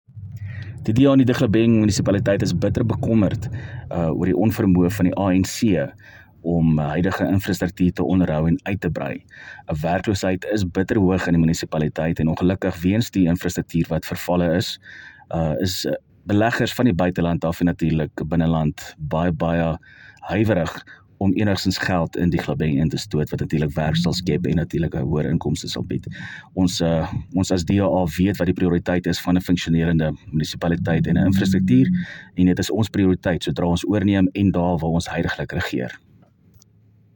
Afrikaans soundbites by Cllr Willie Theunissen and